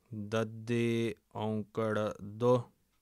muharni - A Clojure library designed to hack up a web page for studying the Punjabi script and the pronunciation thereof approved for the reading of Sikh sacred texts.